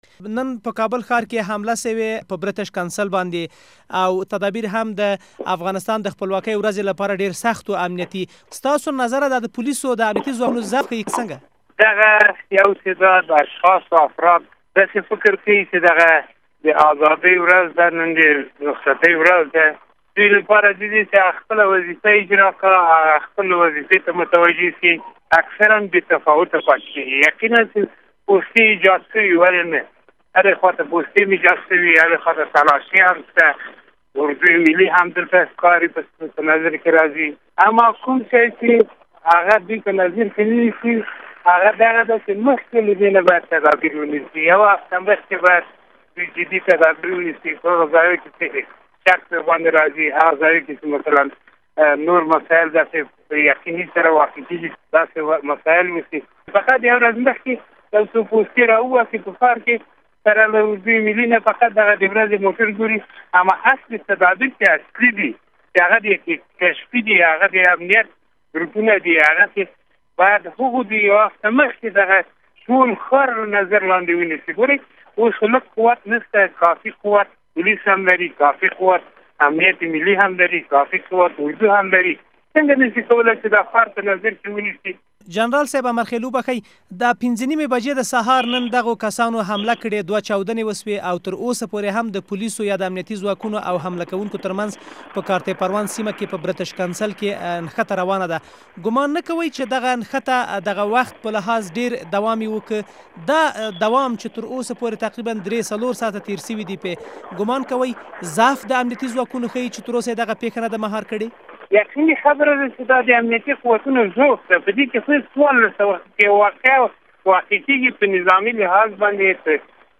له جنرال امرخېل سره مرکه